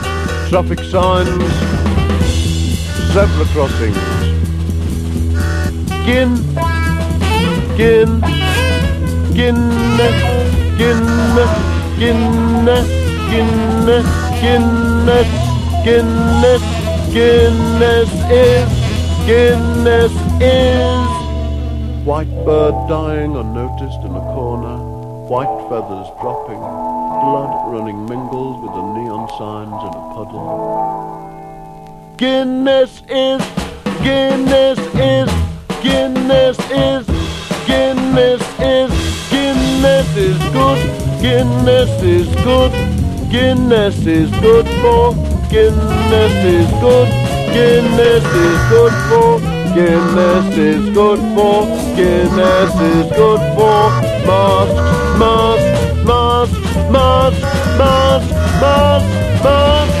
キラーな60'Sガレージ・パンク/ガレージ・サイケをたっぷり収録！